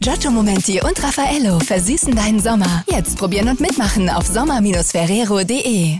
Ferrero Pralinen (euphorisch)